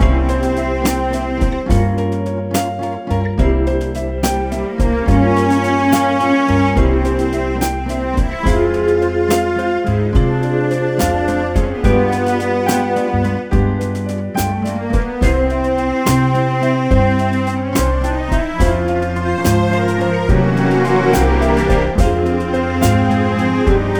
no Backing Vocals Jazz / Swing 2:48 Buy £1.50